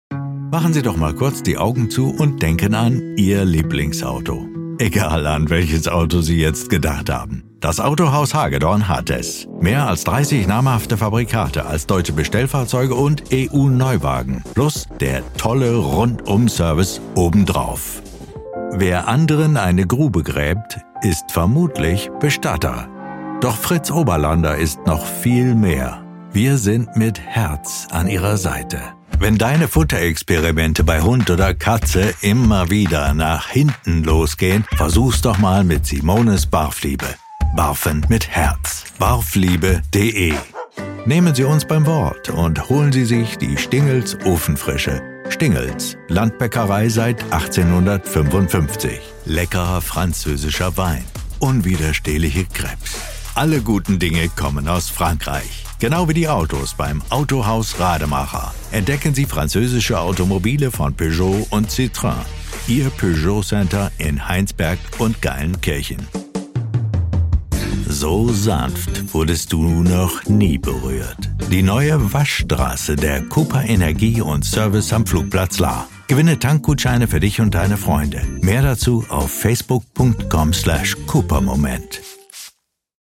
stimmfaerbung: 3 stimmalter: 2 geschlecht: 1 lebenslauf: Stimmbeschreibung: Weich, warm, werblich - authoritär. Oder die sonore Stimme eines Erzählers, vertrauenerweckend, seriös.
Dialekt 1: Kein Dialekt